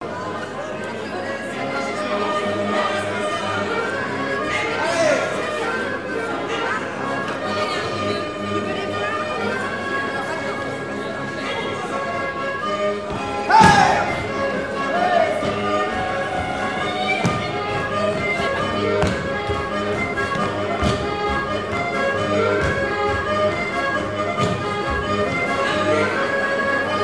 Mémoires et Patrimoines vivants - RaddO est une base de données d'archives iconographiques et sonores.
danse : ronde : grand'danse
Présentation lors de la sortie de la cassette audio
Pièce musicale inédite